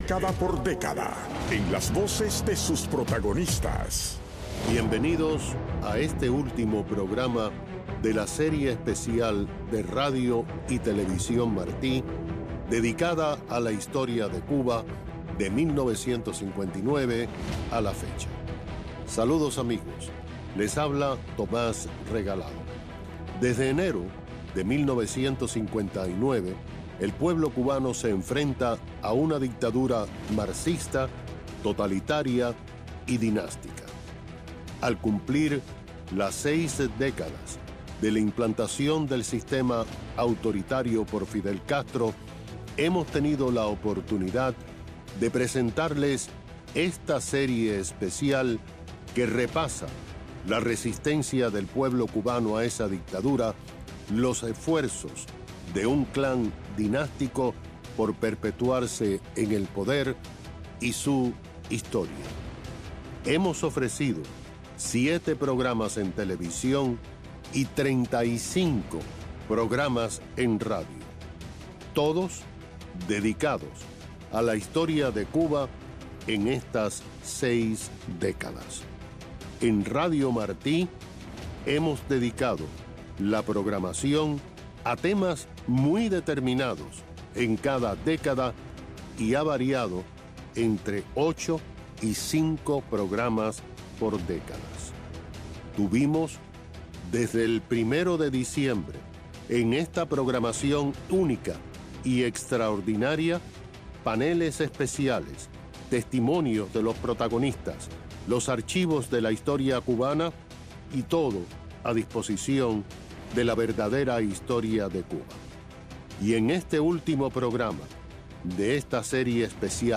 Moderador Tomas Regalado Invitado